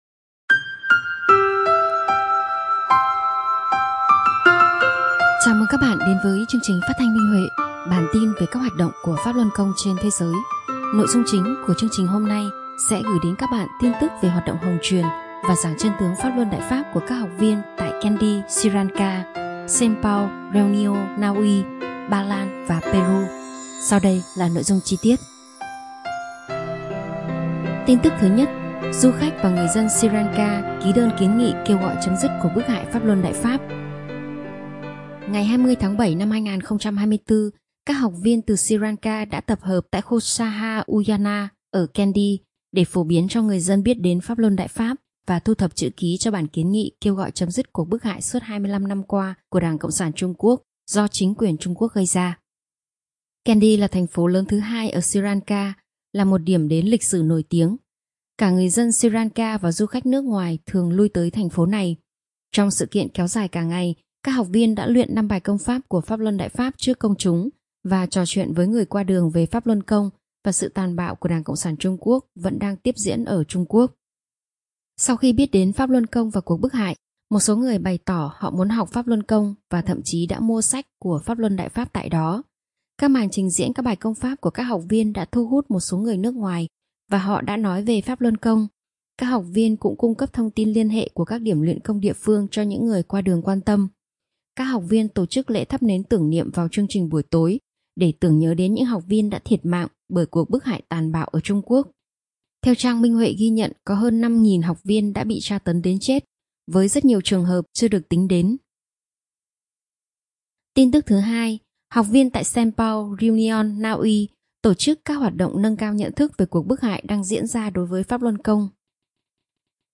Chương trình phát thanh số 202: Tin tức Pháp Luân Đại Pháp trên thế giới – Ngày 5/8/2024